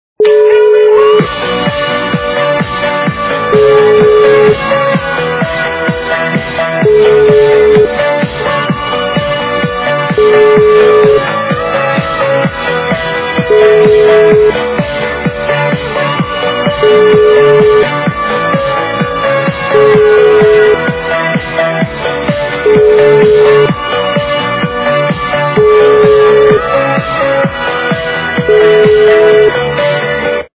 западная эстрада
При заказе вы получаете реалтон без искажений.